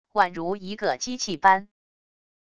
宛如一个机器般wav音频